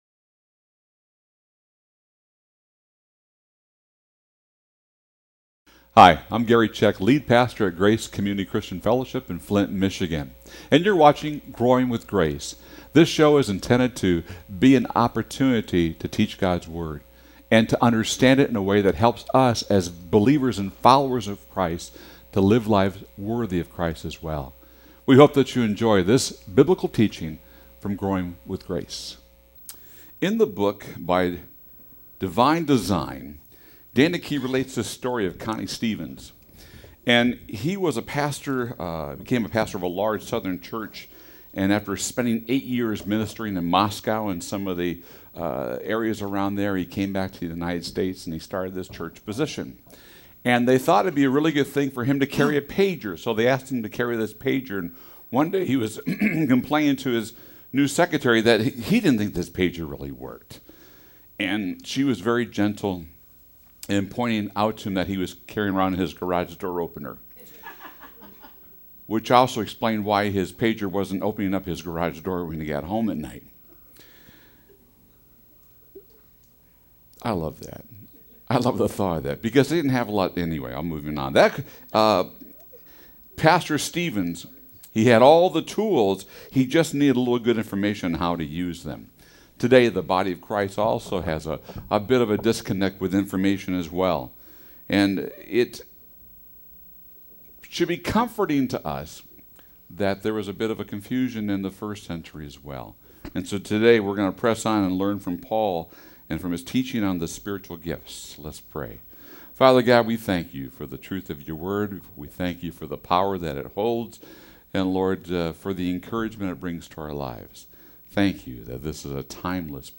An Expository Study Through 1st Corinthians